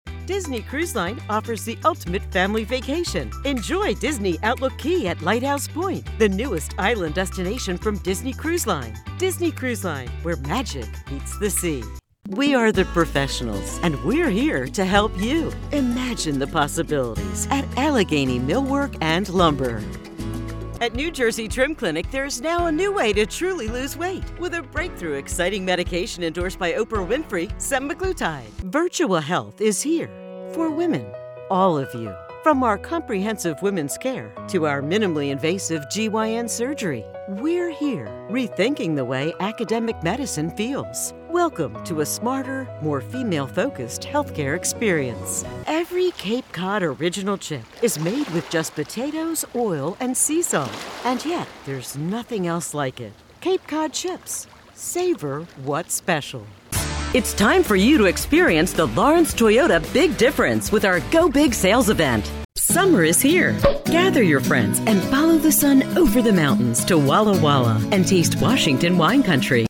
Announcer , Articulate , Believable , Female , Mid-Range , Versatile Description Description http